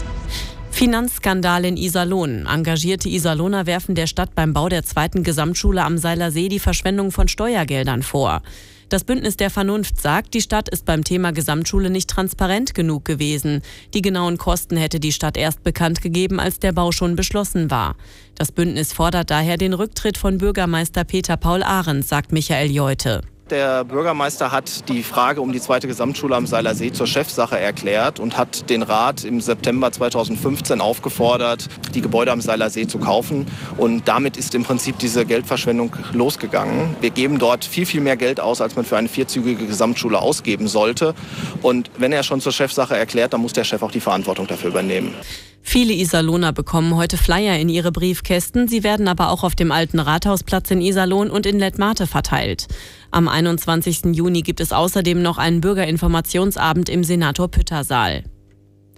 Unser Lokalradio war natürlich gleich am 10.06.2017 vor Ort und hat schon bei der Verteilaktion O-Töne gesammelt und über uns berichtet!